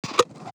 Reload.wav